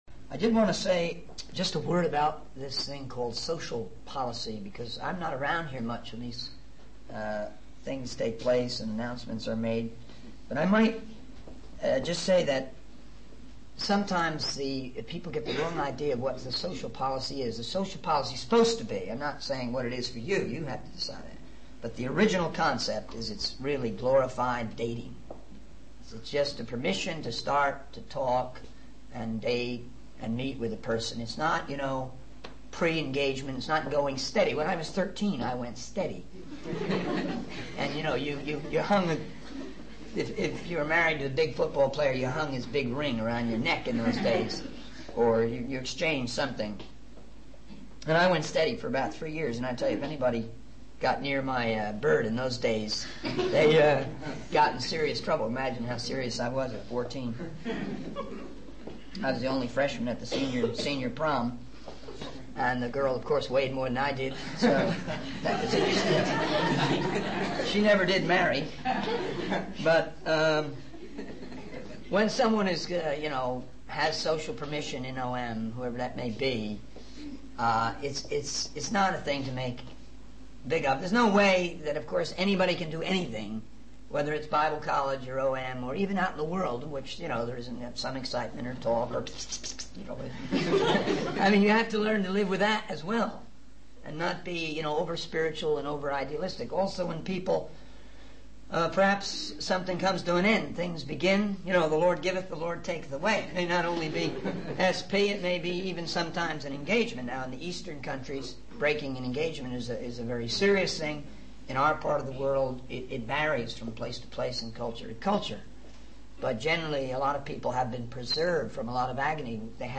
In this sermon, the speaker discusses the importance of understanding the organization and vision of OM (Operation Mobilization) for those considering long-term ministry within it. The speaker emphasizes the need for leaders to grasp a deeper understanding of OM's strategy and how it functions.